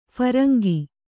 ♪ pharangi